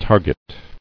[tar·get]